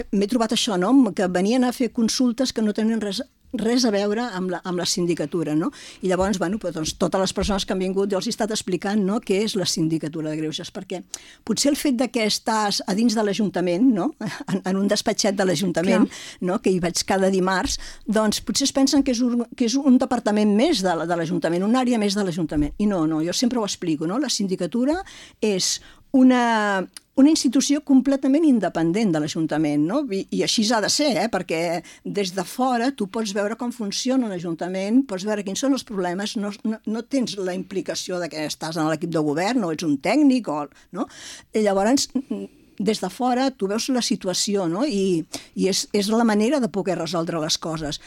Un any després de la seva primera entrevista com a Síndica de Greuges, la Cel·la Fort torna a La Local per fer balanç del seu primer any al càrrec i comentar l’informe que va presentar en el ple del mes de gener.